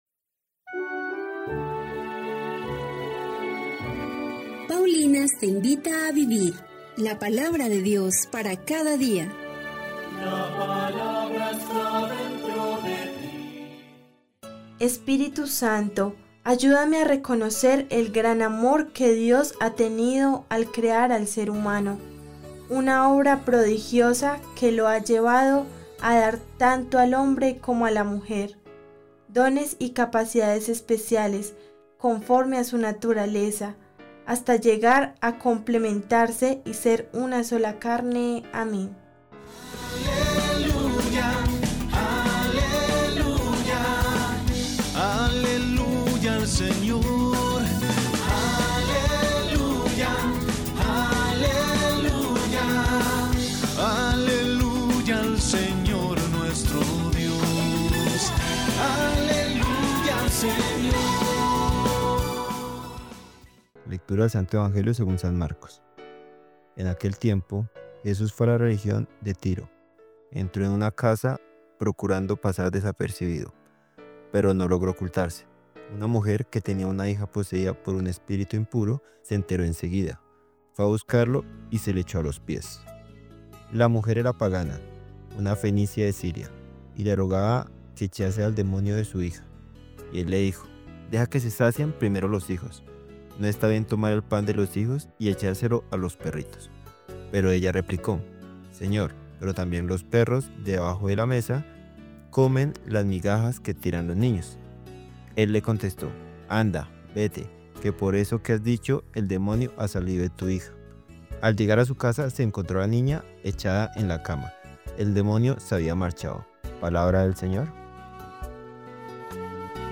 Lectura de la carta del apóstol Santiago 1, 12-18